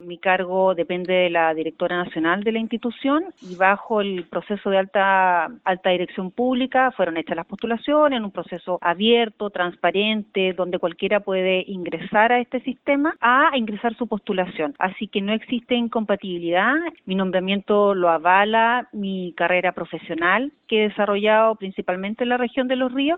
Sin embargo, en conversación con La Radio, la nueva directora regional del Sernameg, Loreto Cerda, desmintió las acusaciones del diputado Gastón von Mühlenbrock, justificando su arribo al organismo al señalar que depende de la directora nacional, Priscilla Carrasco, y de Alta Dirección Pública, descartando que exista incompatibilidad en su nombramiento.